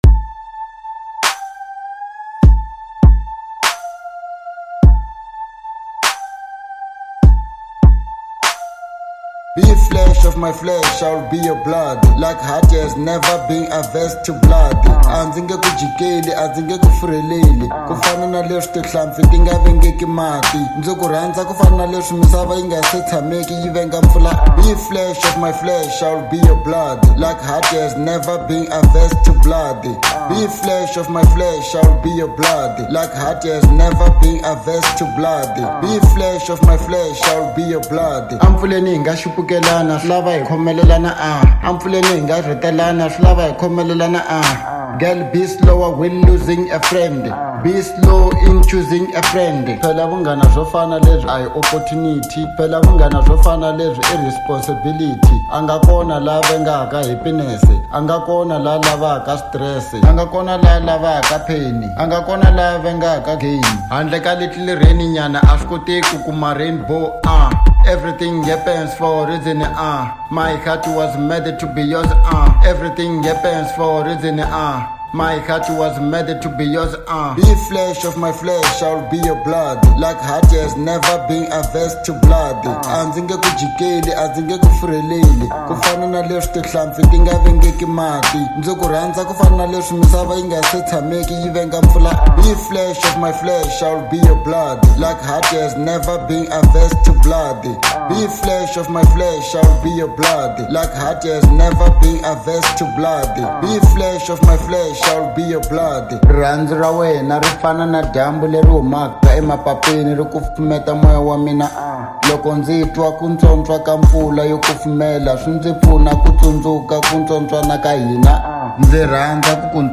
03:45 Genre : Hip Hop Size